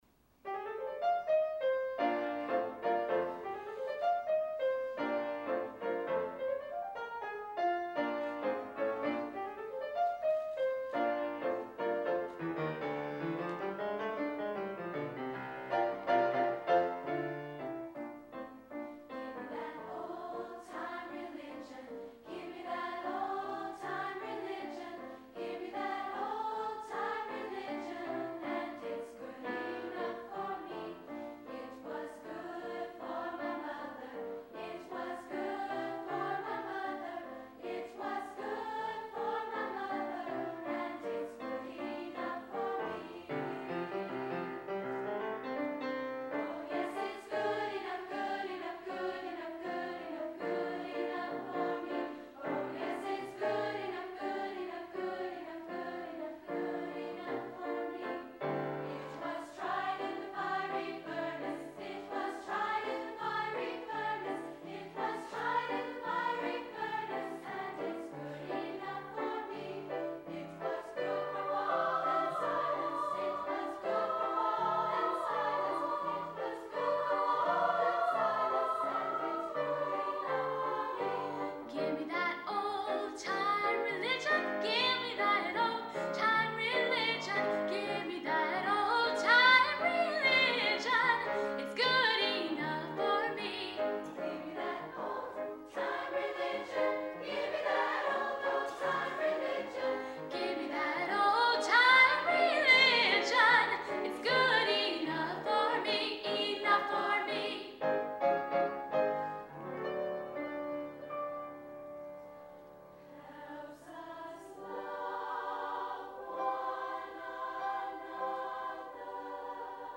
Final Festival